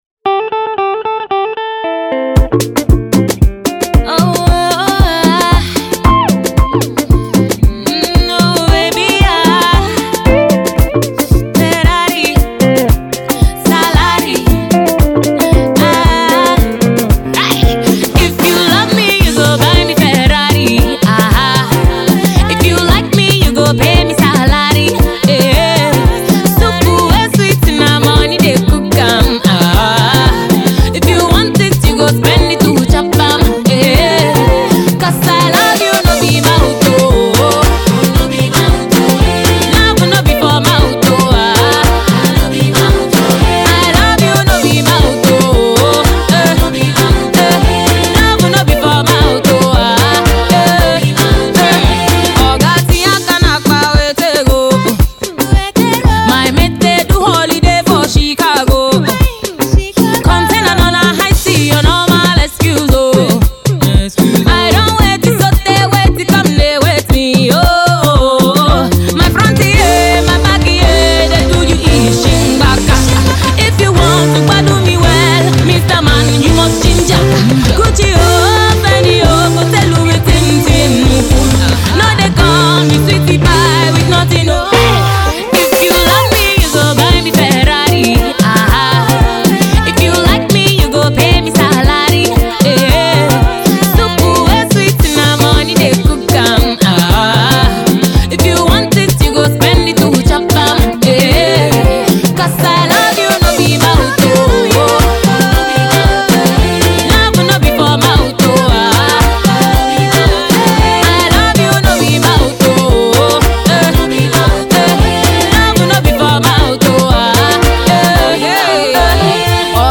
guitar strings
stellar highlife number